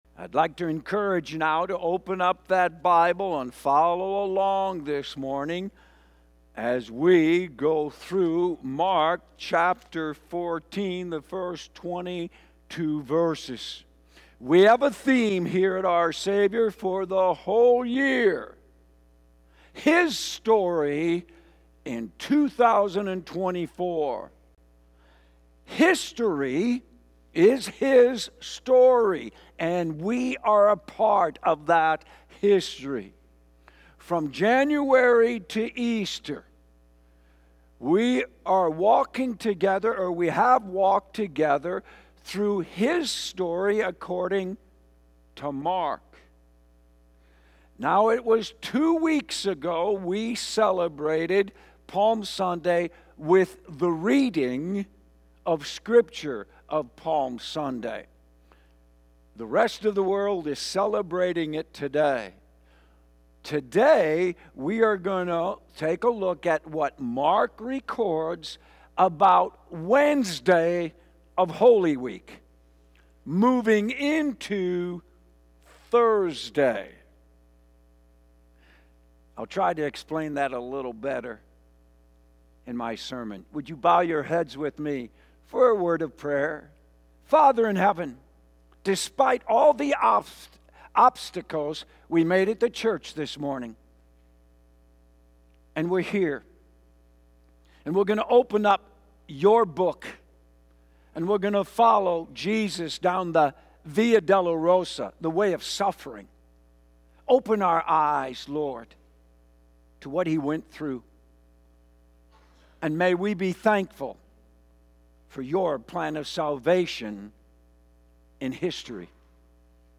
Sunday message from Mark 14:1-21